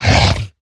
Minecraft Version Minecraft Version latest Latest Release | Latest Snapshot latest / assets / minecraft / sounds / mob / hoglin / attack1.ogg Compare With Compare With Latest Release | Latest Snapshot
attack1.ogg